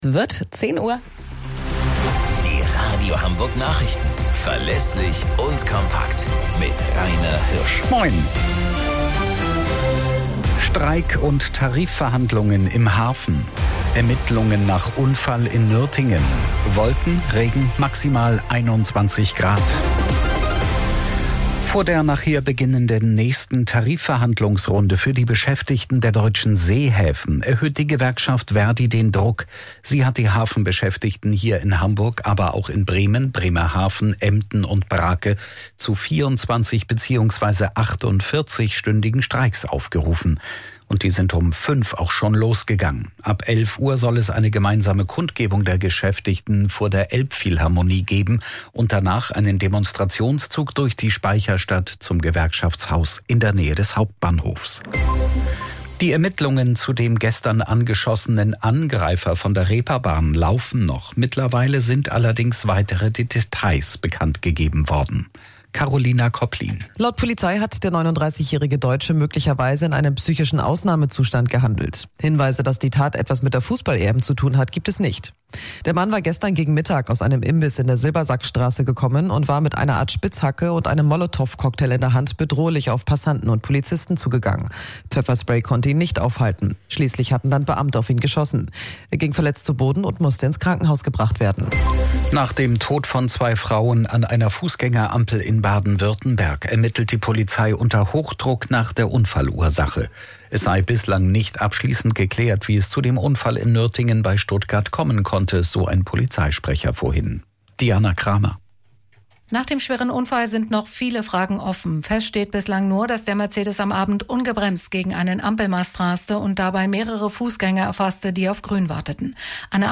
Radio Hamburg Nachrichten vom 17.06.2024 um 17 Uhr - 17.06.2024